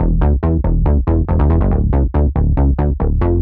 Index of /musicradar/french-house-chillout-samples/140bpm/Instruments
FHC_SulsaBass_140-E.wav